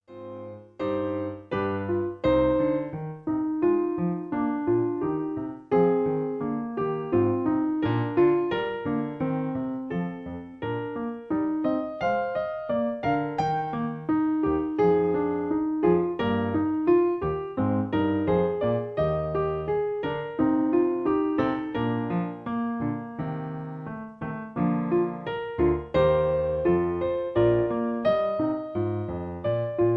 Original key (E flat). Piano Accompaniment